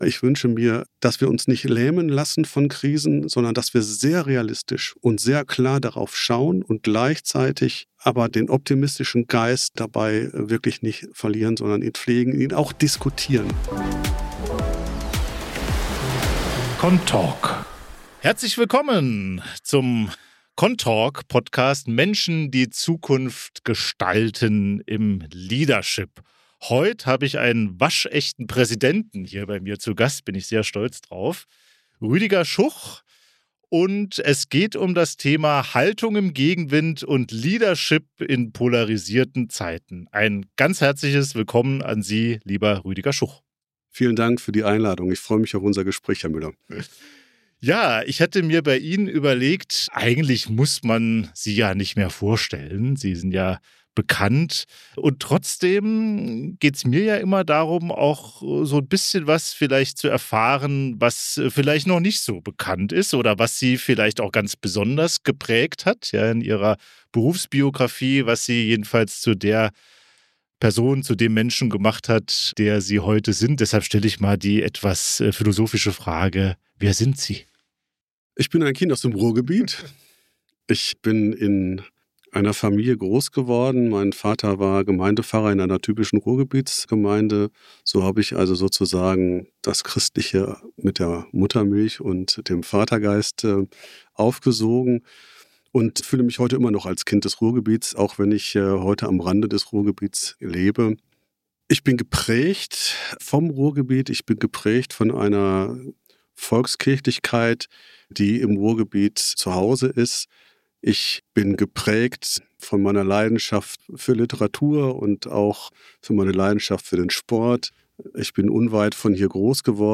Das Gespräch kreist um Führung in Krisenzeiten, um Verständigungsorte in einer polarisierten Gesellschaft und um die Frage, welche Qualitäten die nächste Generation von Führungskräften in Kirche, Diakonie und Sozialwirtschaft braucht.